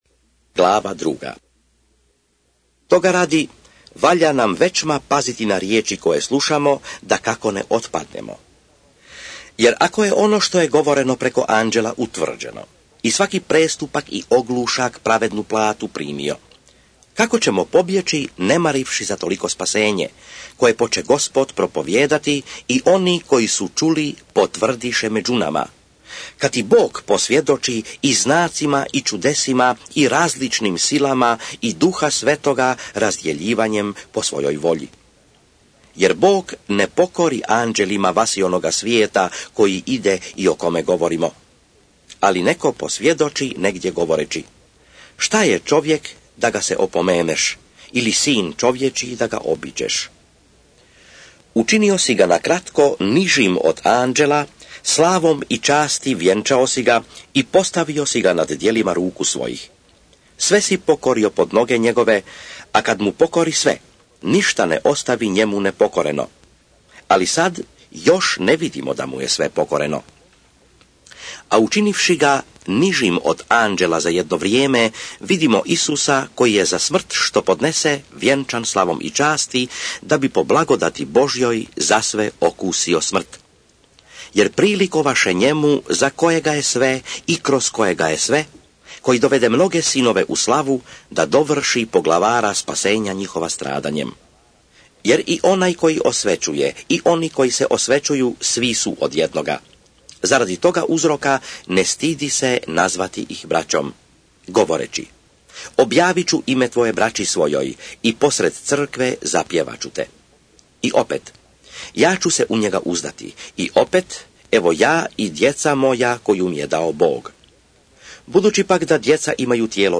JEVREJI(ČITANJE) - Bible expounded
SVETO PISMO – ČITANJE – Audio mp3 JEVREJI glava 1 glava 2 glava 3 glava 4 glava 5 glava 6 glava 7 glava 8 glava 9 glava 10 glava 11 glava 12 glava 13